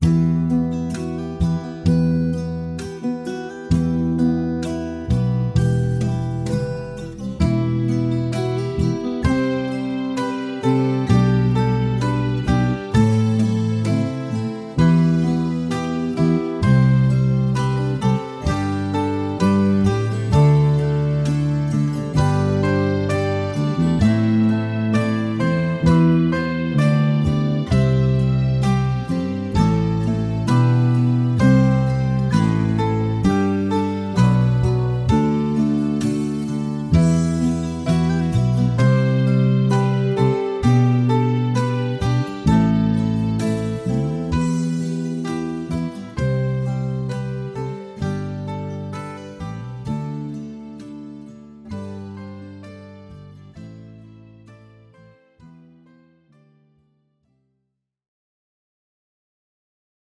Latviešu tautas dziesma Play-along.